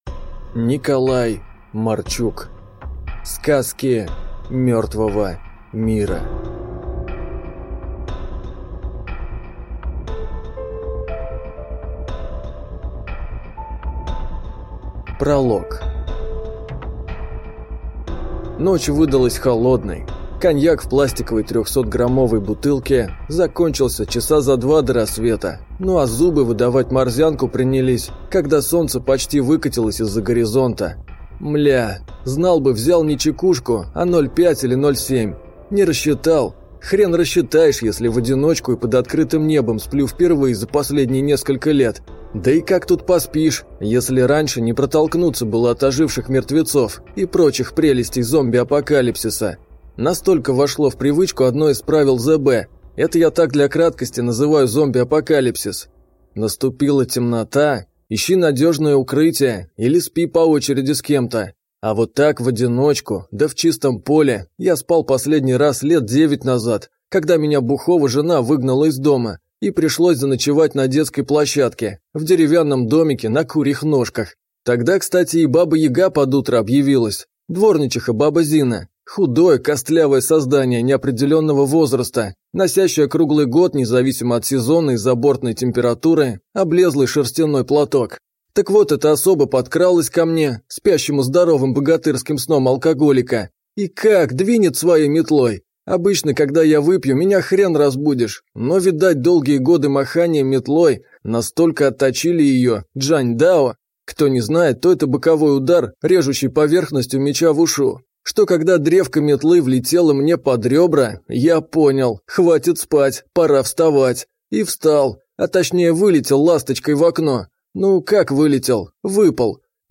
Аудиокнига Сказки мертвого мира | Библиотека аудиокниг